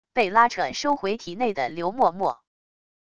被拉扯收回体内的流墨墨wav音频